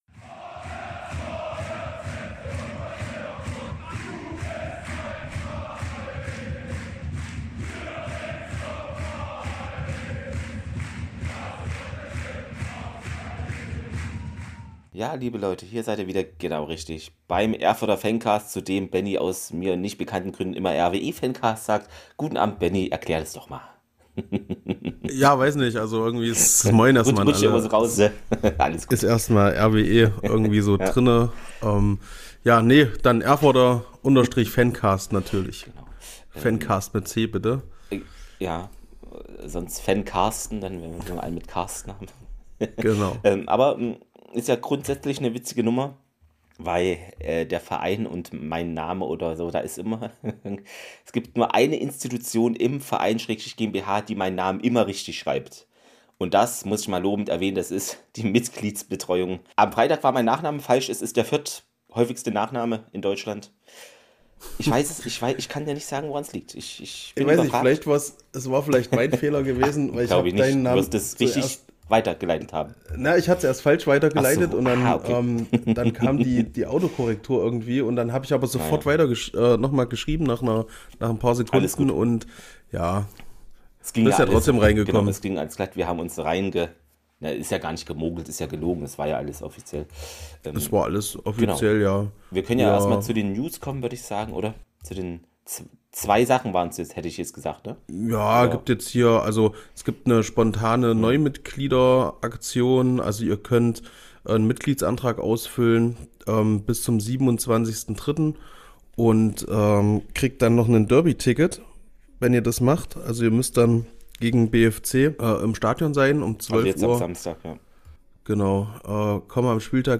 Freitagabend: Bei bestem Fußballwetter und Flutlicht geben wir unser Debüt beim RWE Live-Radio. Eine Folge über Undämpfbare Fans, einen Otto, der sich breit macht und volle Offensivpower. RWE gewinnt mit 4:1 gegen Hertha II!